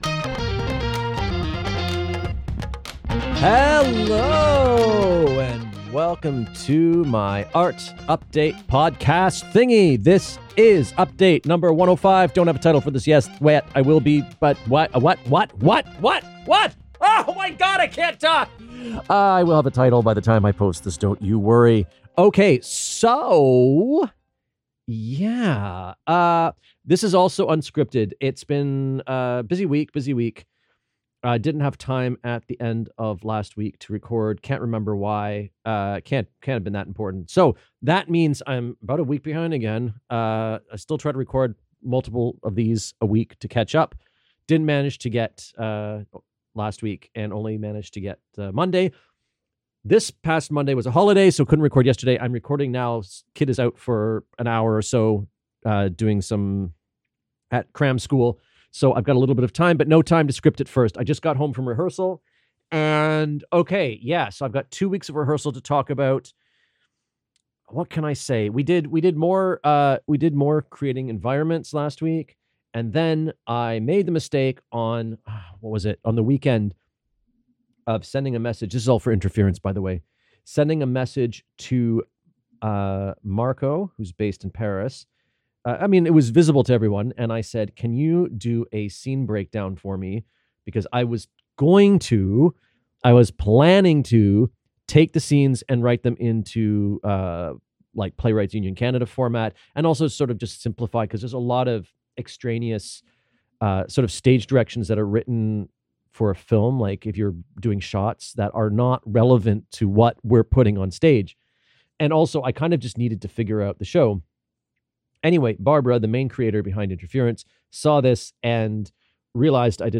Another unscripted update in which I talk about the previous week's rehearsals (and yesterday's).